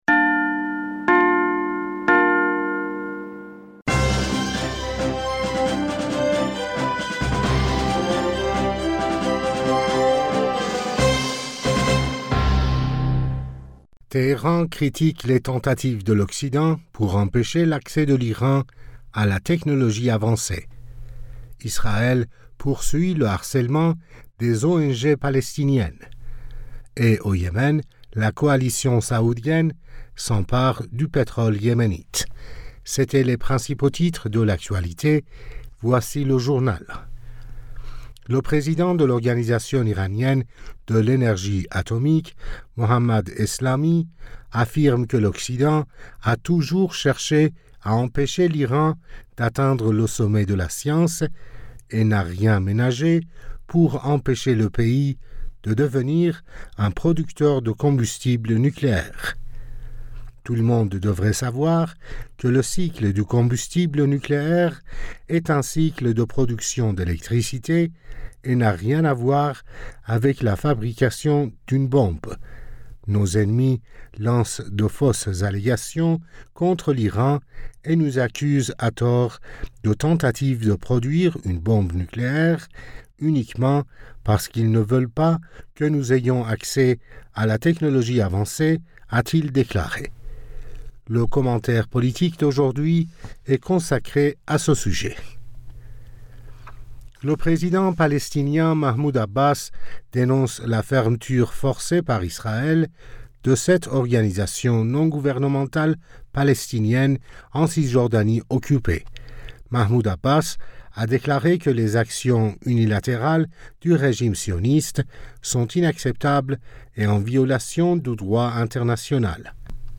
Bulletin d'information Du 21 Aoùt